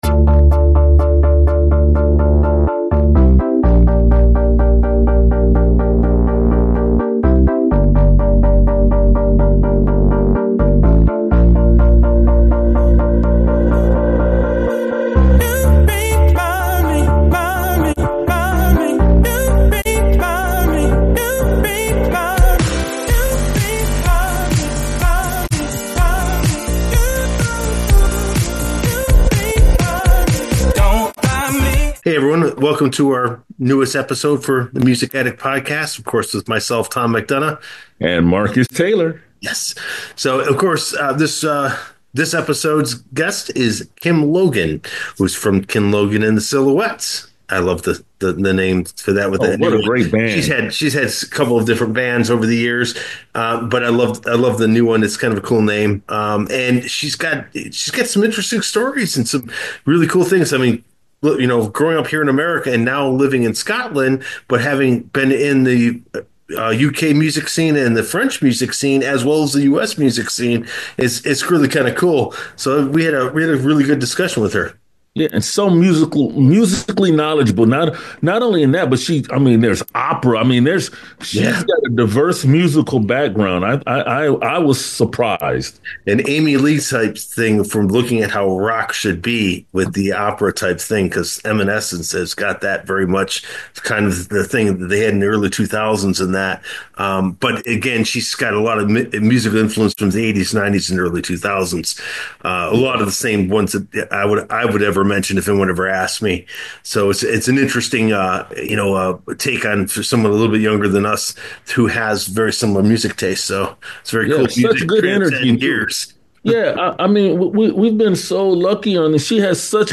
we dive into an electrifying conversation